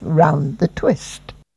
Index of /90_sSampleCDs/Zero-G - Total Drum Bass/Instruments - 3/track61 (Vox EFX)